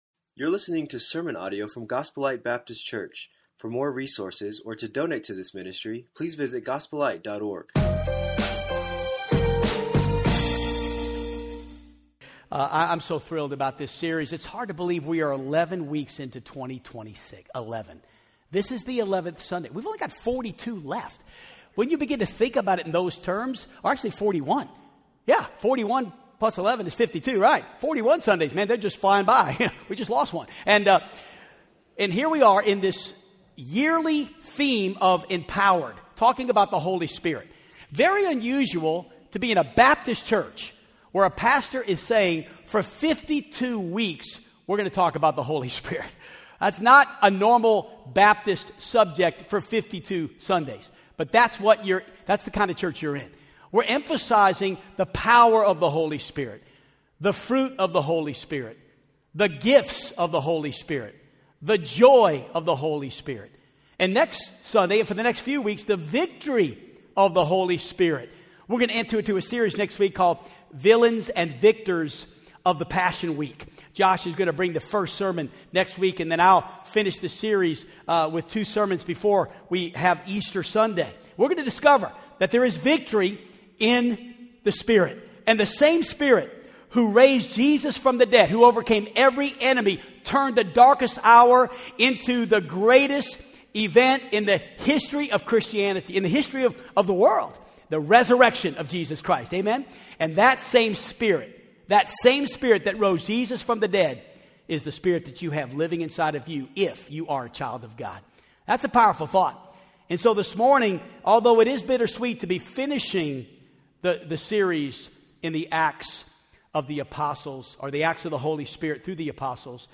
Acts of the Holy Spirit - Sermon 10